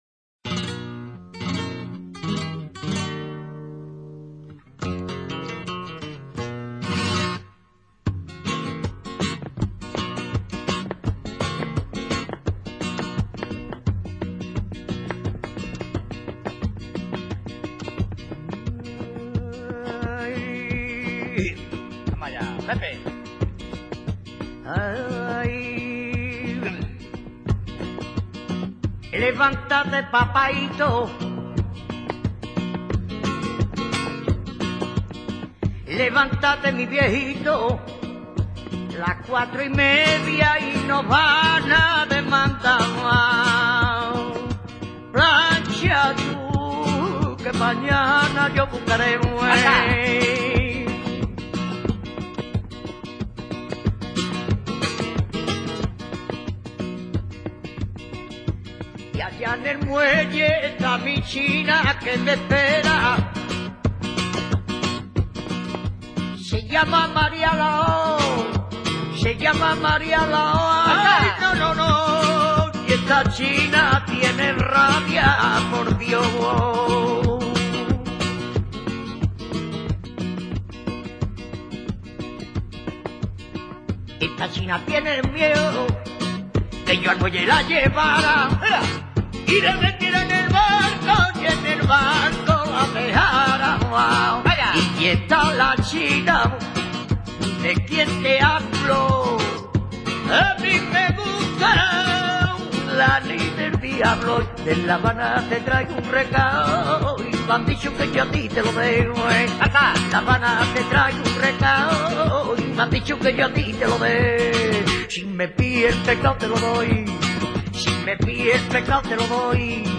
Sonidos y Palos del Flamenco
Cante folkl�rico aflamencado con copla de cuatro versos generalmente hexas�labos.
rumba.mp3